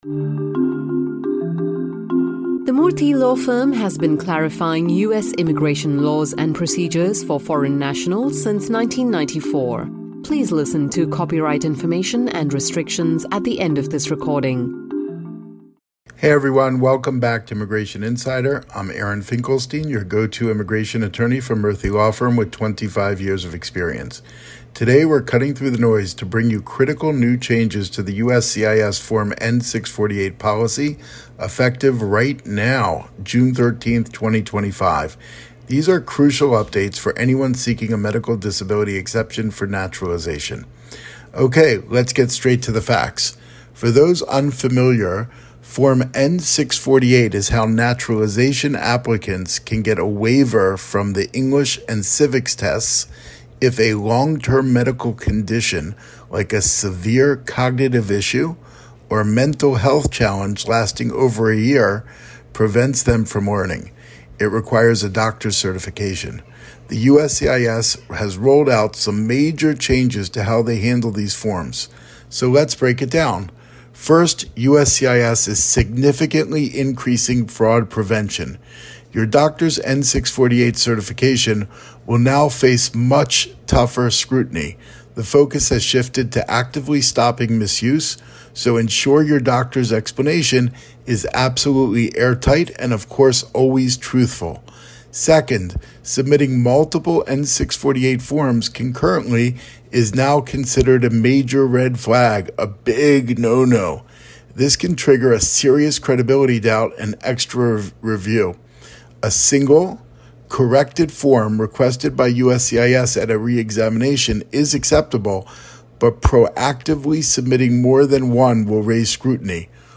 Changes to the N-648 policy are effective NOW! This brief presentation by an experienced senior attorney at the Murthy Law Firm brings listeners up to date on the medical disability exception for naturalization.